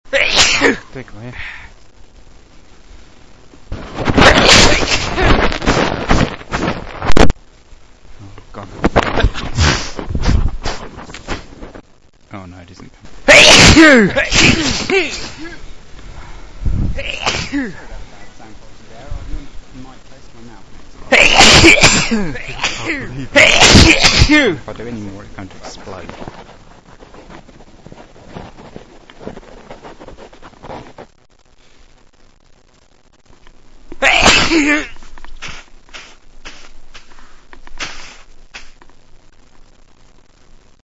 MALE WAVS